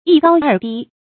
一高二低 yī gāo èr dī
一高二低发音